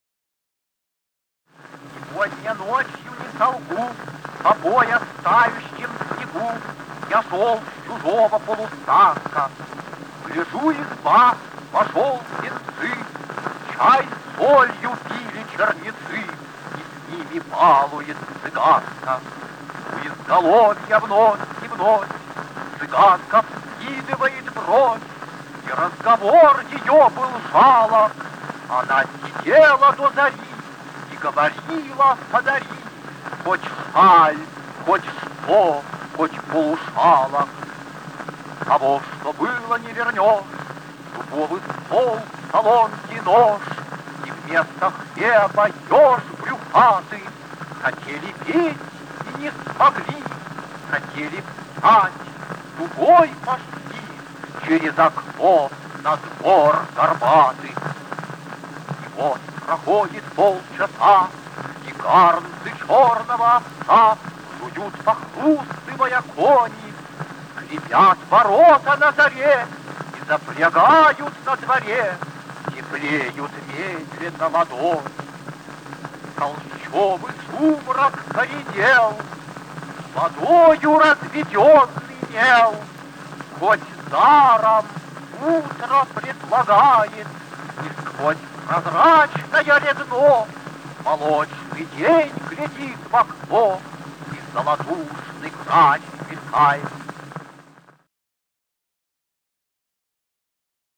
3. «Читает Осип Мандельштам – Сегодня ночью, не солгу…» /
chitaet-osip-mandelshtam-segodnya-nochyu-ne-solgu